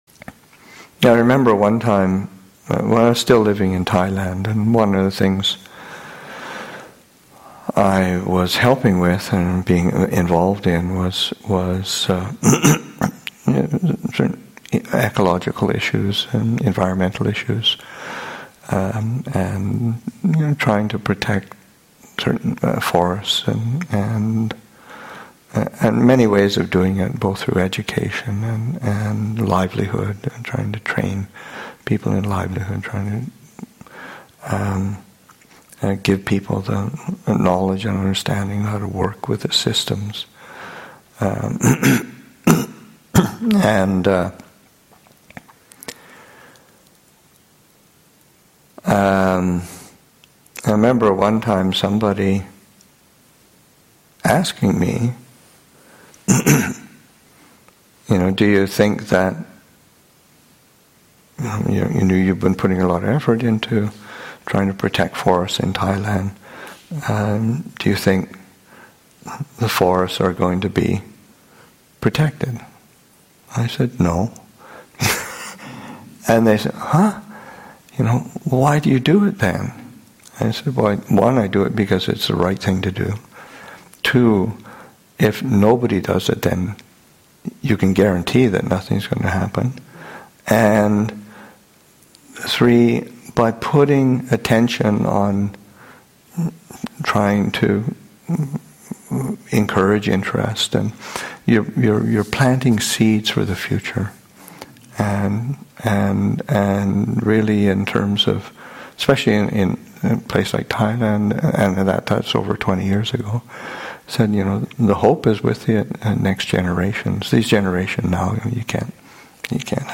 Upāsikā Day, Aug. 12, 2017
Abhayagiri Buddhist Monastery in Redwood Valley, California and online